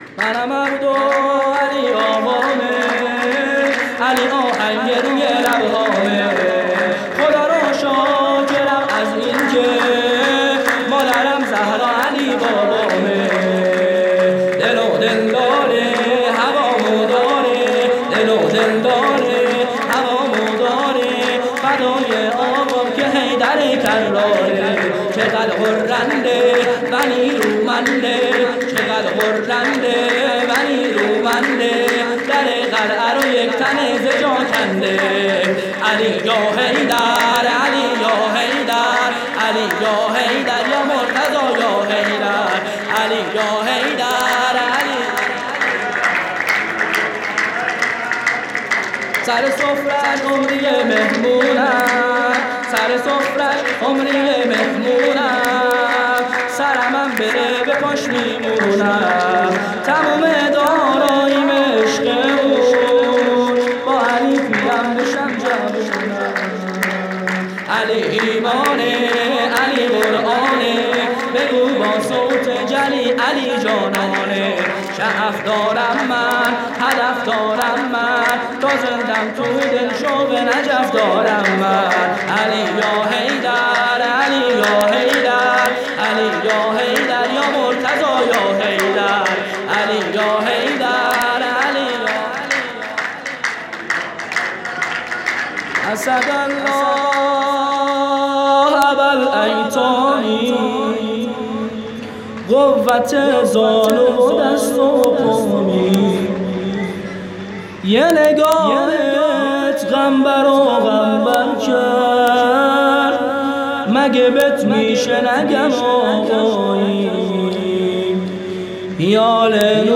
گزارش صوتی عید غدیر ۹۸